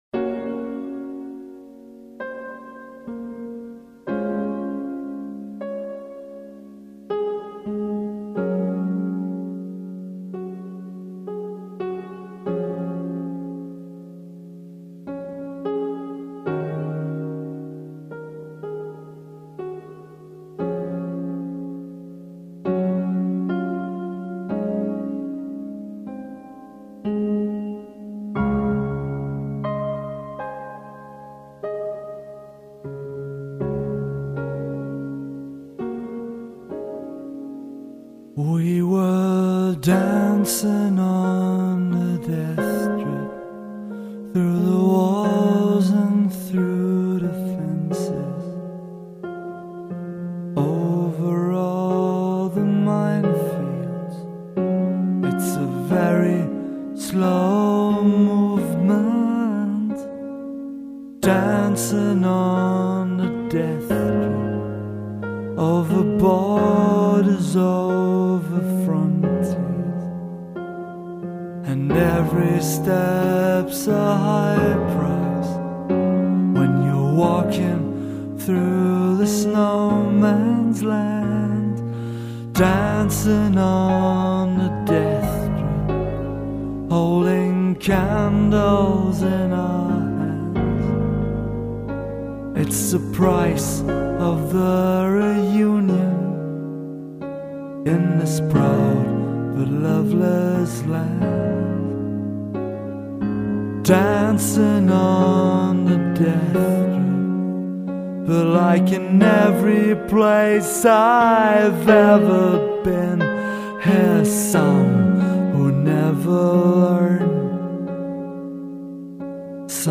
(Ballade)